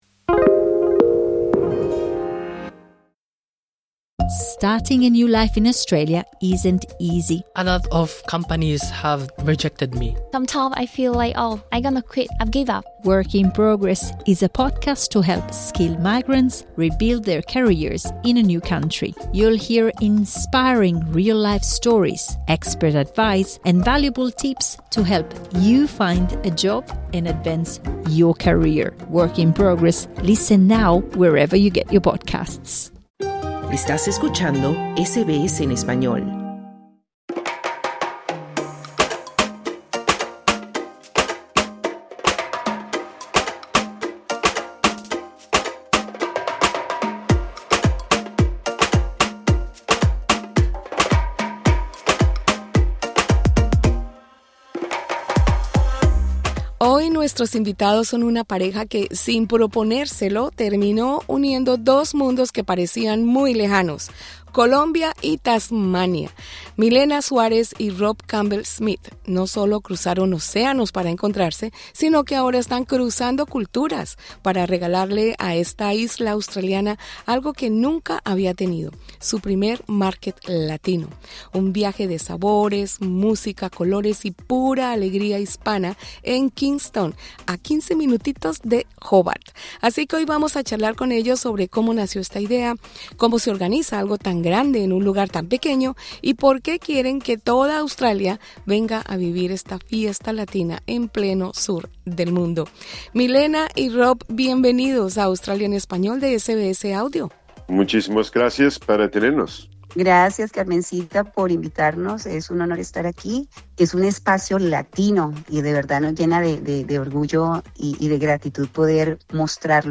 Tasmania será escenario del primer Latin Market VIVO, un evento cultural que reunirá sabores, artesanías, música y talento latino en el Kingborough Community Hub este 30 de noviembre. Escucha la entrevista con los organizadores del evento.